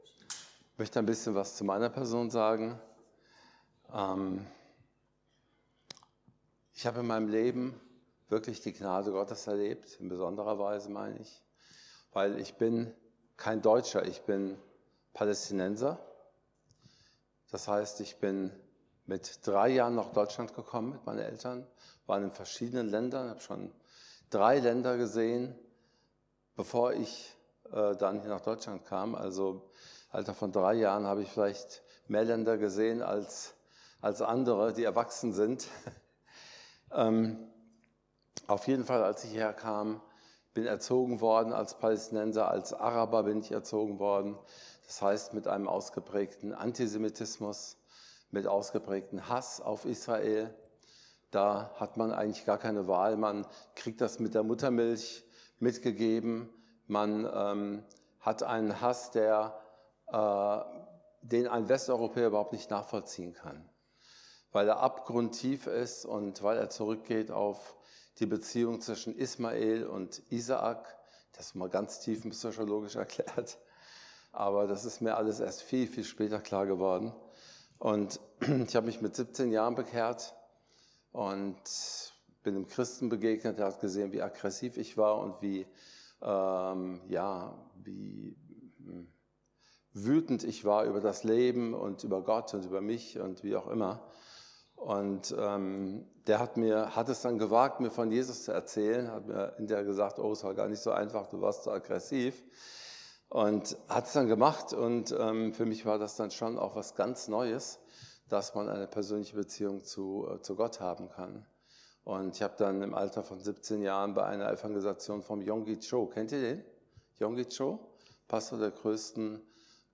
Jes. 49,8 Dienstart: Predigt Gib die Gnade Gottes an Andere weiter.